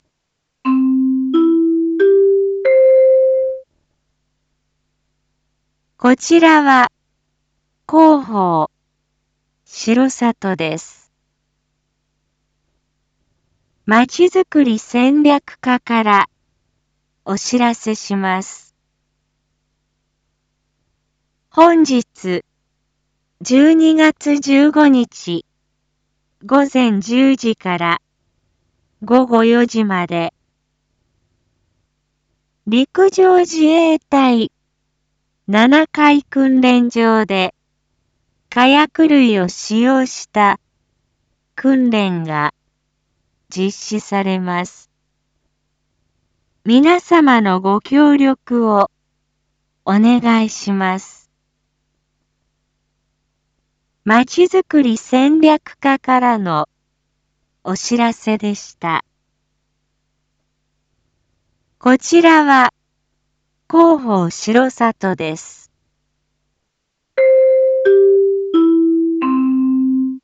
一般放送情報
Back Home 一般放送情報 音声放送 再生 一般放送情報 登録日時：2022-12-15 07:01:11 タイトル：R4.12.15 7時放送分 インフォメーション：こちらは広報しろさとです。